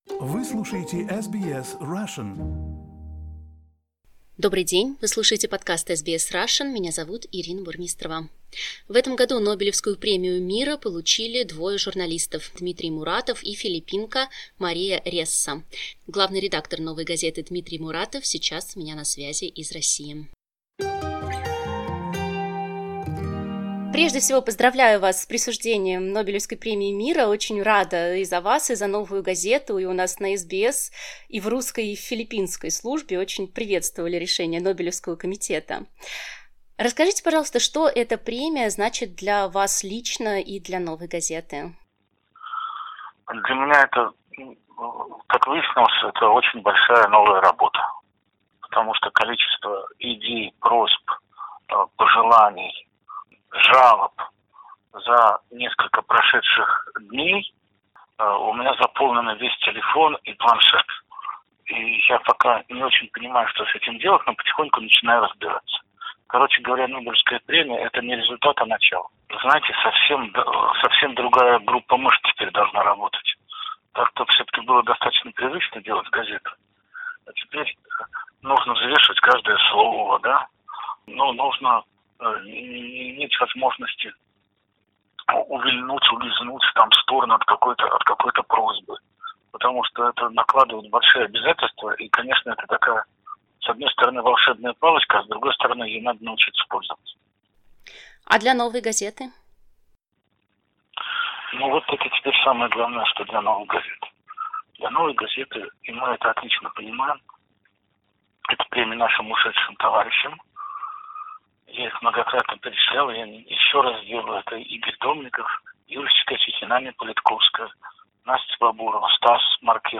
В интервью SBS Russian один из лауреатов Нобелевской премии мира 2021 года, главный редактор Новой газеты Дмитрий Муратов рассказал о том, как награда изменила его жизнь, а также о состоянии российской журналистики и взаимодействии властей с независимой прессой.